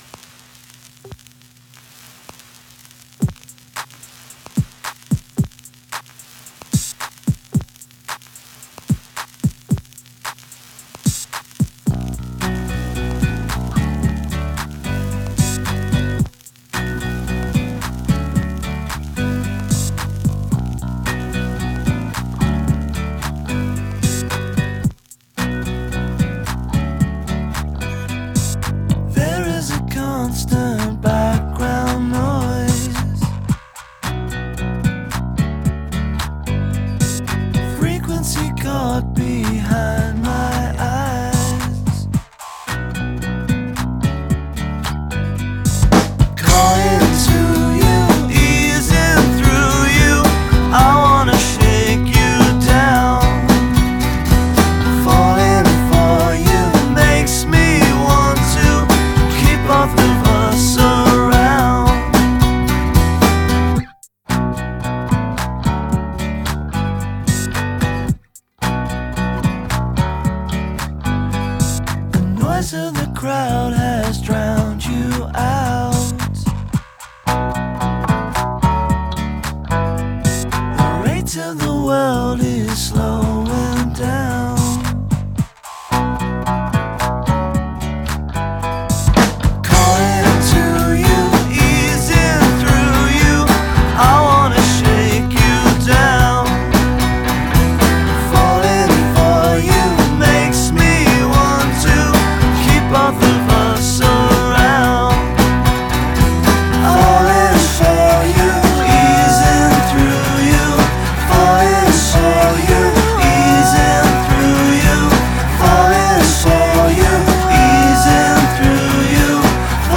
Genre: electronic, folk.